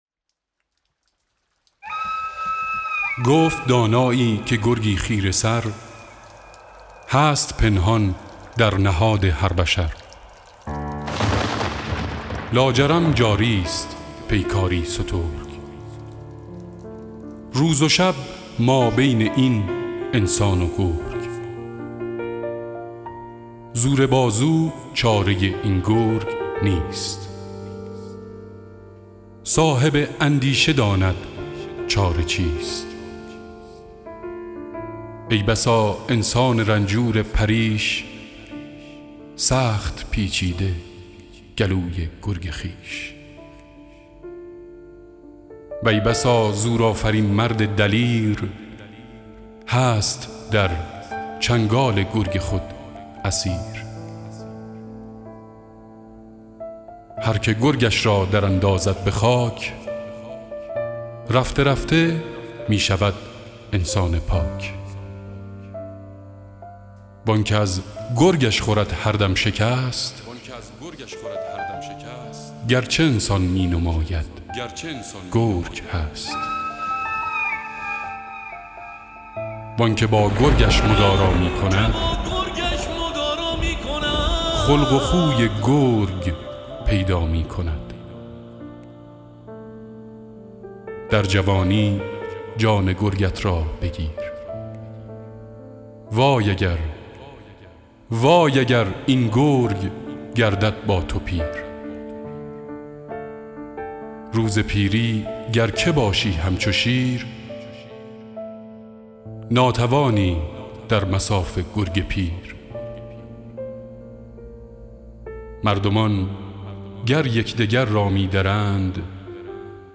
دکلمه الهی نامه و گرگ و انسان
بسيار عالى بود،متن دكلمه فوق العاده بود صداى گرم و دلنشين شما هم زيباتر كرده بود،واقعا لذت بردم ،با آرزوهاى موفقيت روز افرون براى شما🌹👏